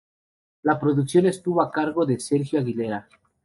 Pronounced as (IPA) /ˈkaɾɡo/